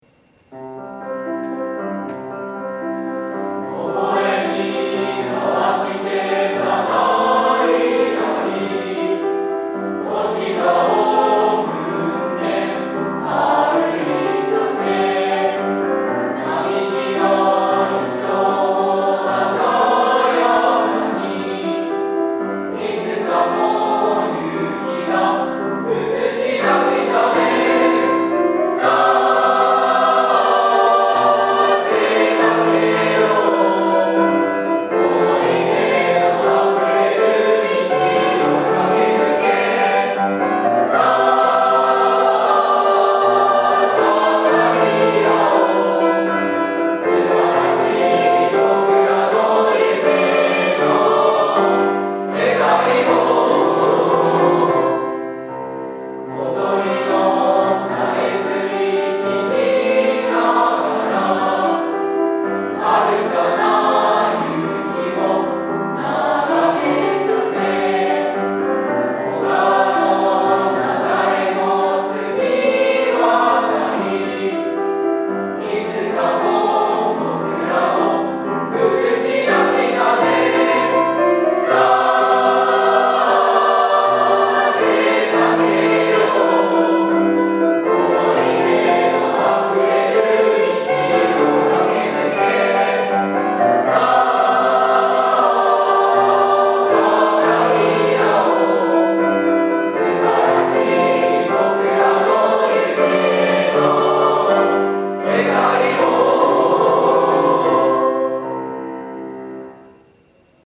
合唱コンクール(音声あり)
20日(木)に行われました合唱コンクールの音声になります。